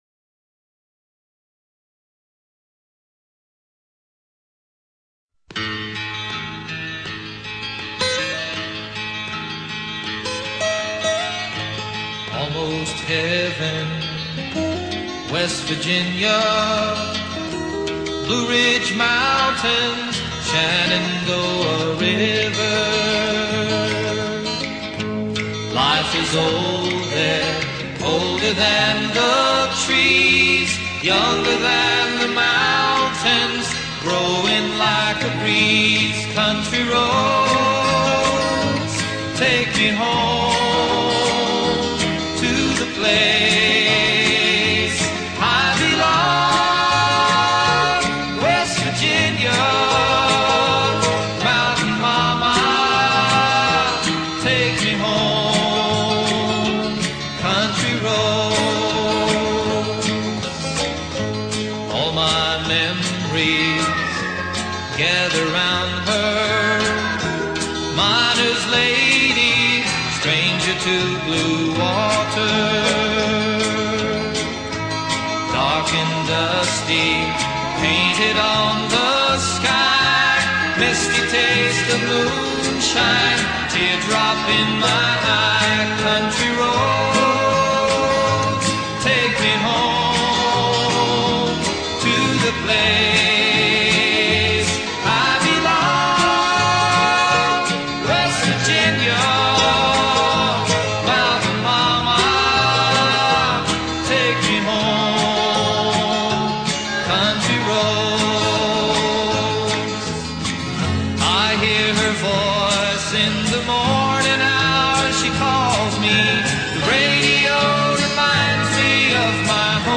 LA MÚSICA COUNTRY.